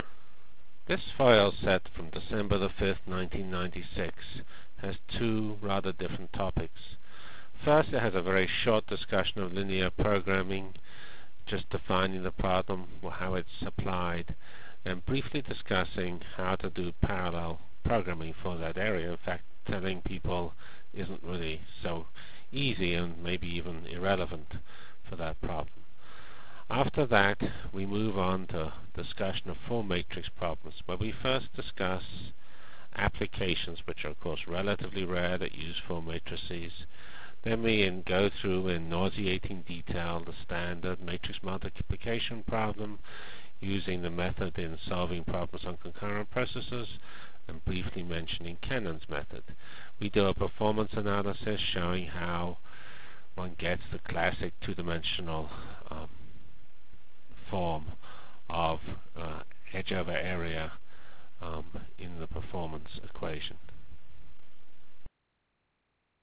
Lecture of December 5 - 1996